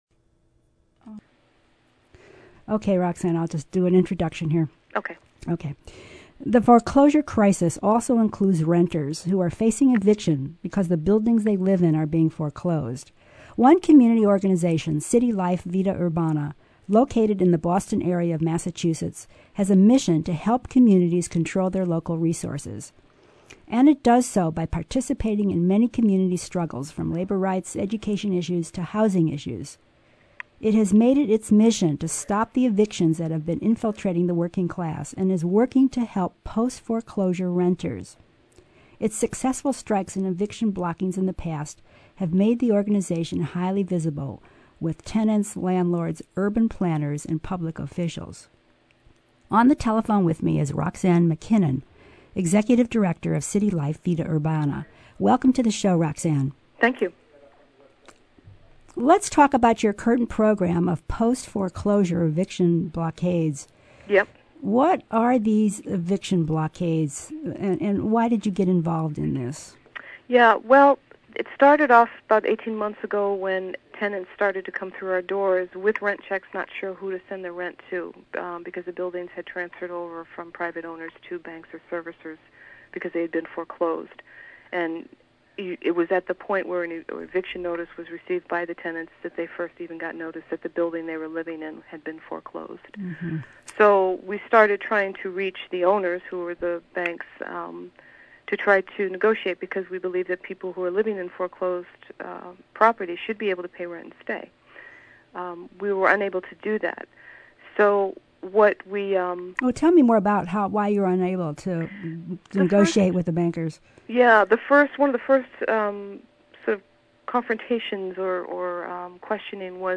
Interview with Vida Urbana - Radical Housing Rights Activists